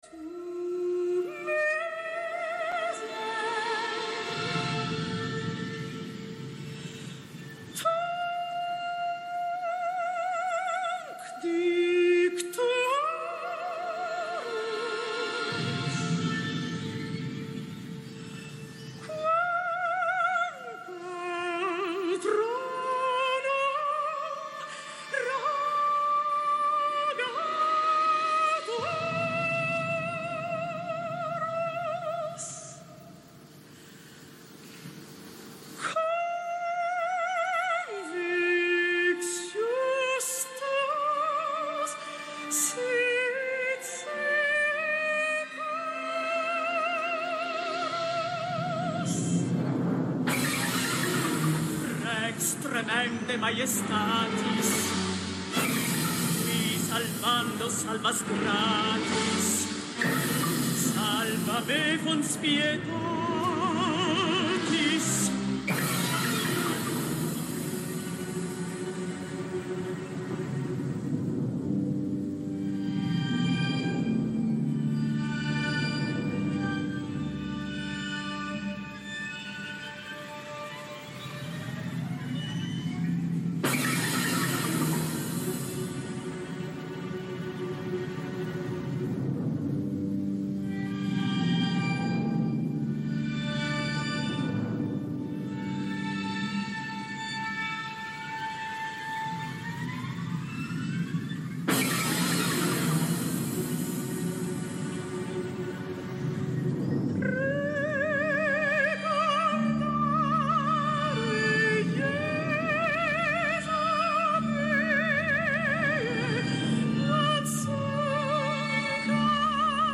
Incontro con la compositrice italiana